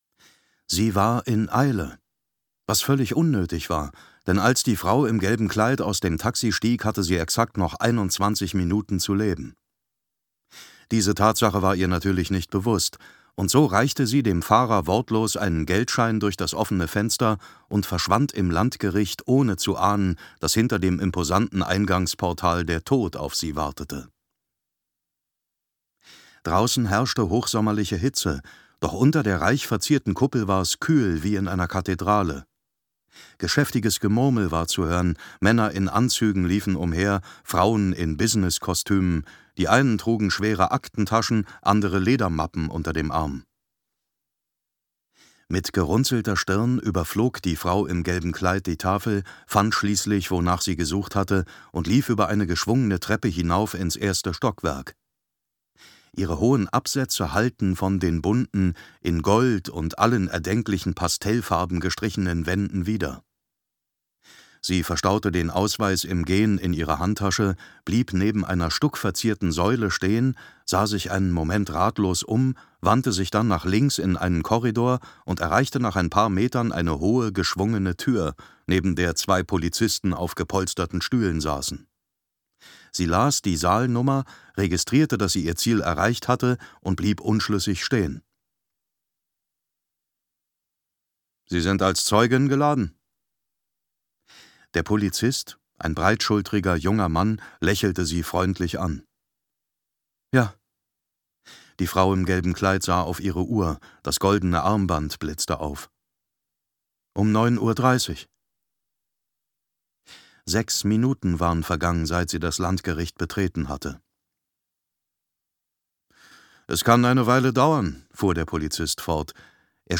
Gekürzt Autorisierte, d.h. von Autor:innen und / oder Verlagen freigegebene, bearbeitete Fassung.
Zorn - Zahltag Gelesen von: David Nathan